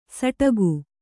♪ saṭagu